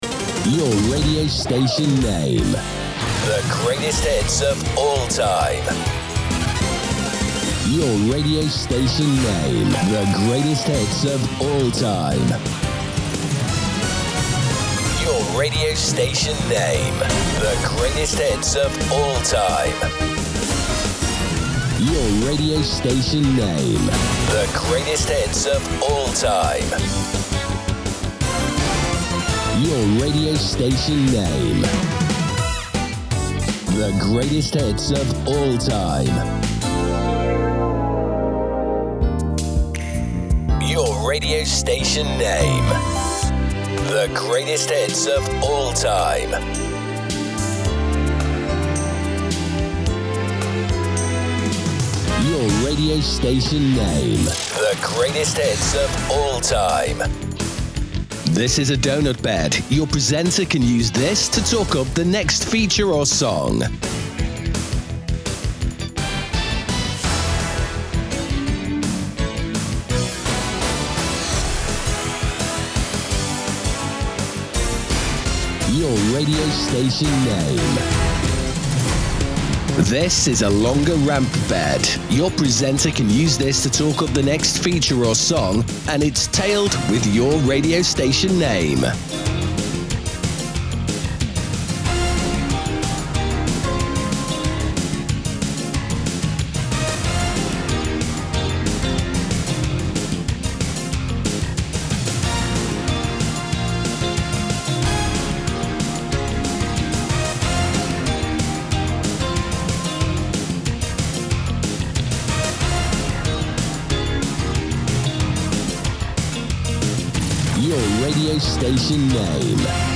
Special Offer – 10 Fully Produced Jingles
The cuts will sound the same as the demo below, and is suitable for a classic oldies radio station.